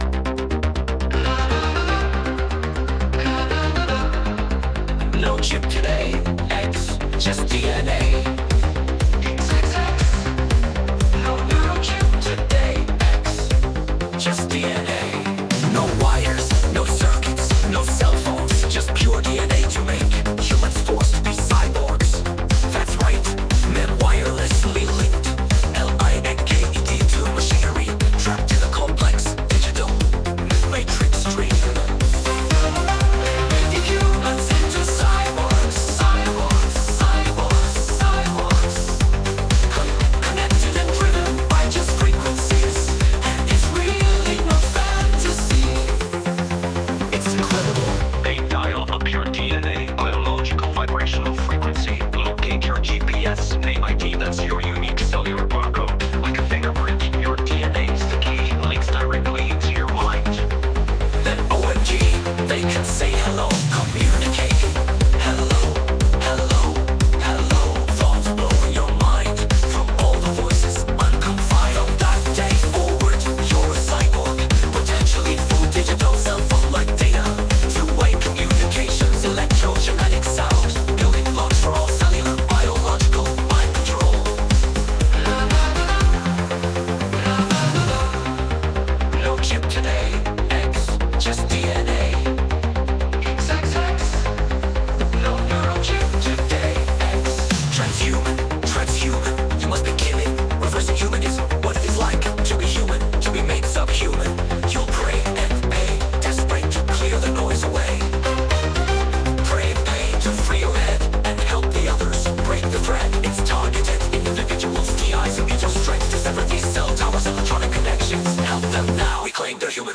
Driving 1980's Disco-popmelodiccatchychorus in minor